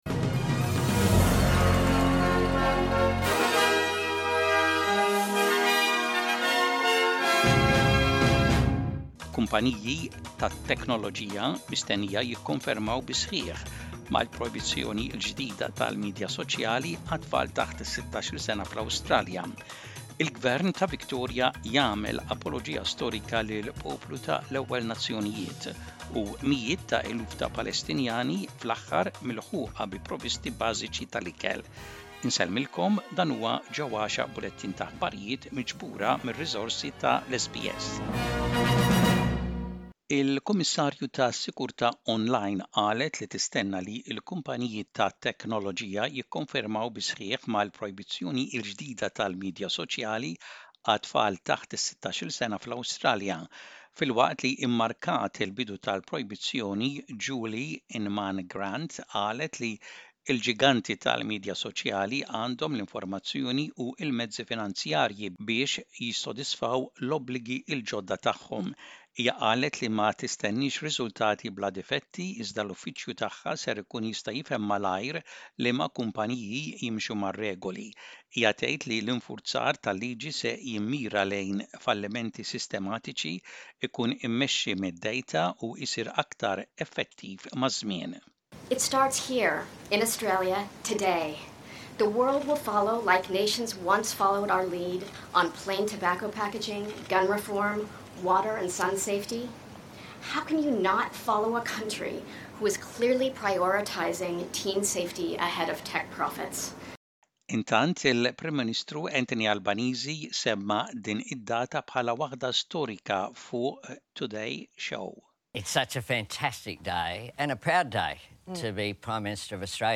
SBS Maltese News - Image SBs Maltese